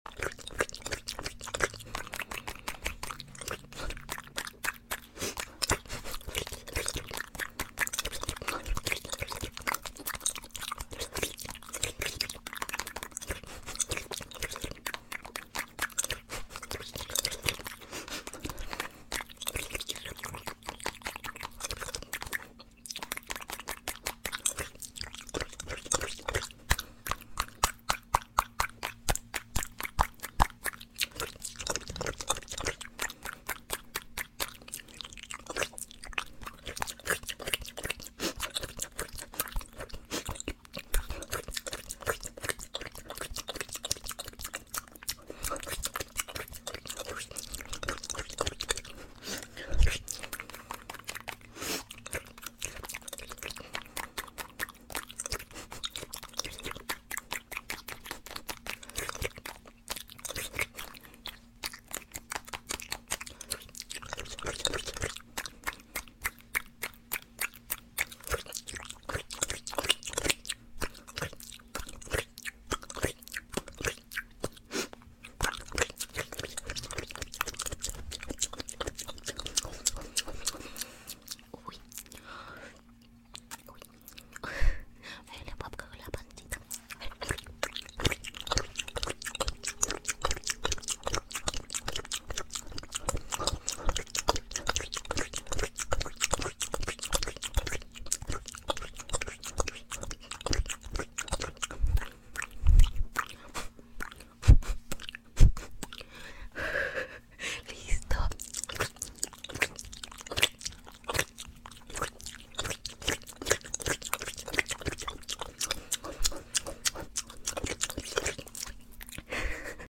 asmr